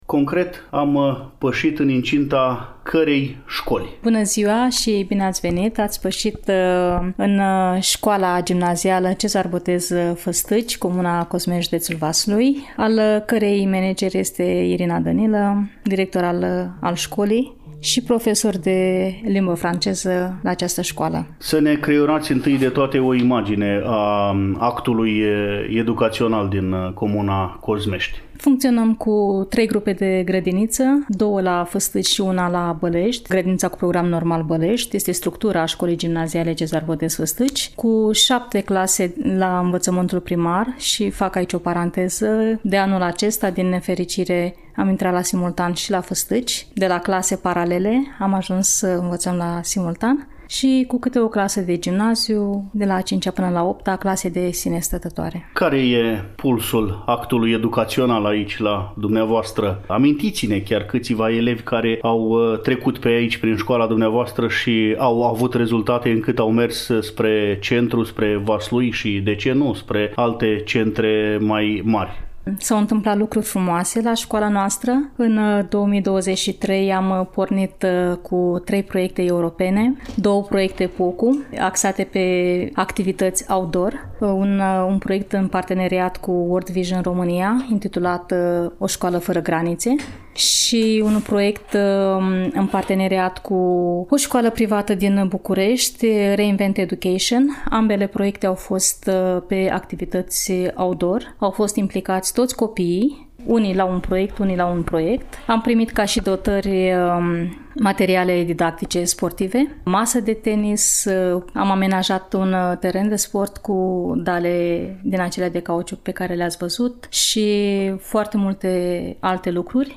În următoarele minute, facem popas în incinta Școlii Gimnaziale „Cezar Botez” din satul Fâstâci, Cozmești.